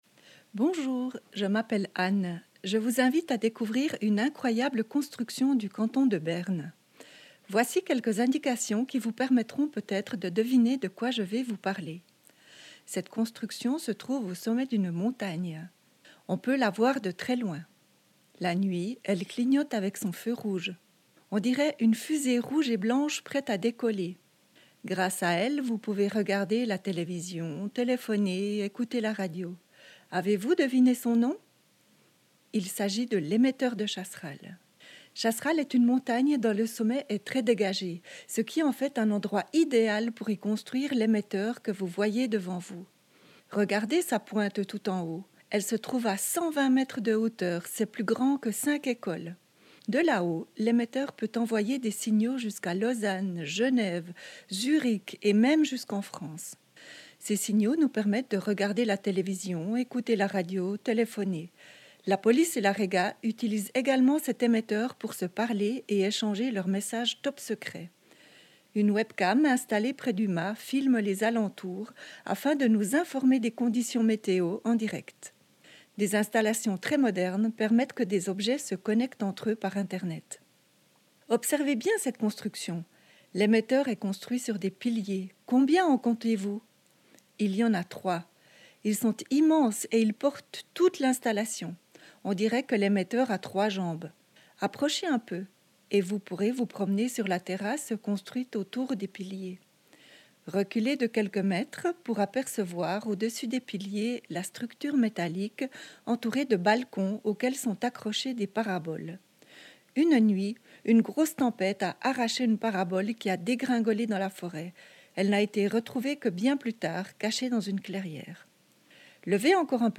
AUDIOGUIDE L'ÉMETTEUR DU CHASSERAL (RNS7)